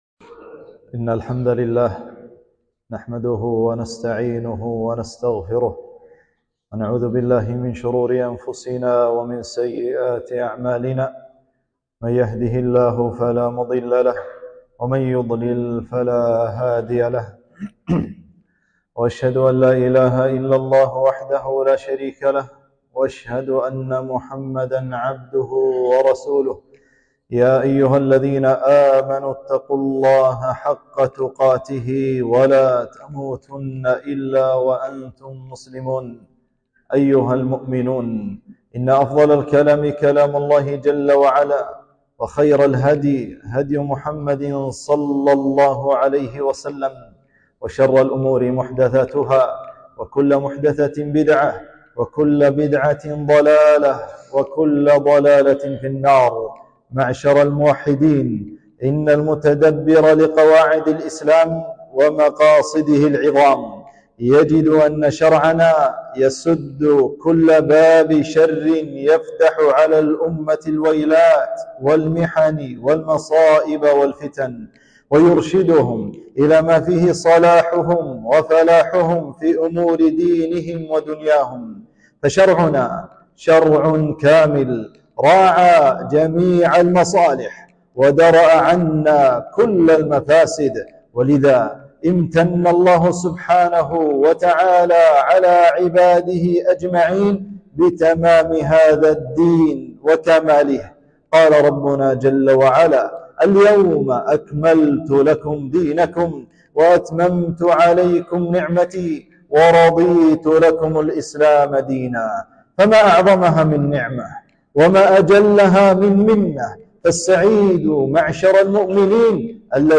خطبة - مكانة الحكام واحترامهم ونصيحتهم